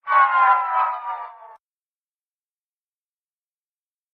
* Adjusted Vox DeathGasp and sigh to be more auditorily distinct from one-another
vox_DeathGasp.ogg